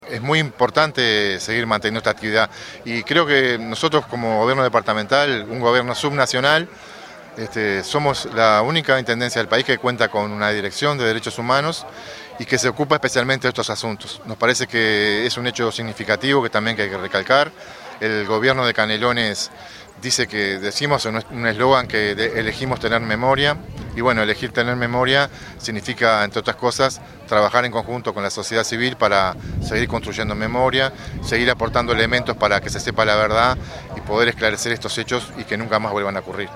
Durante la conmemoración del 48° aniversario estuvo presente, en representación del Gobierno de Canelones, el Director de Derechos Humanos, Carlos Garolla, además de representantes de CRYSOL, integrantes de la Comisión por la Memoria de los Fusilados de Soca, familiares de las víctimas, amigos, vecinos y vecinas de la zona.
carlos_garolla_director_de_derechos_humanos.mp3